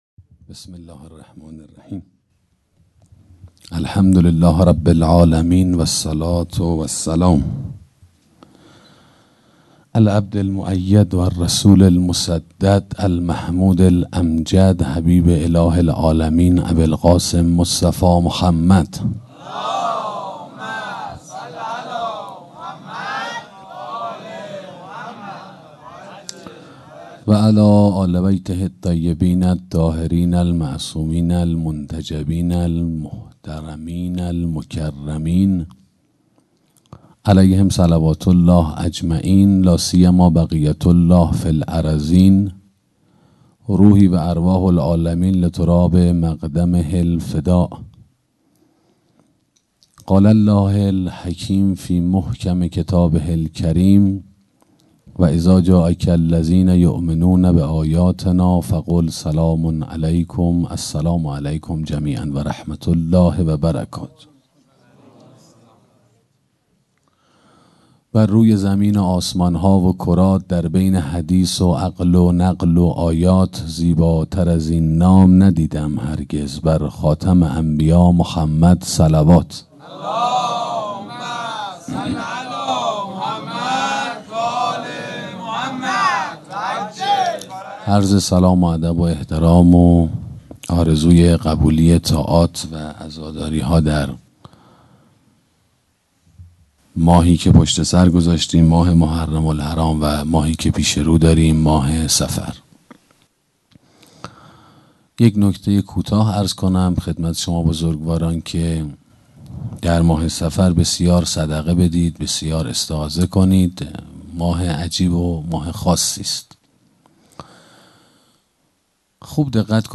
سخنرانی عقل و احساس